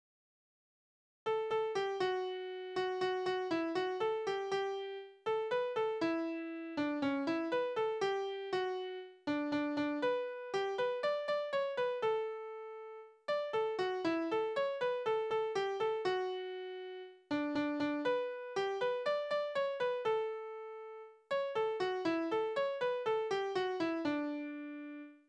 Tonart: D-Dur
Taktart: 4/4
Tonumfang: kleine None
Besetzung: vokal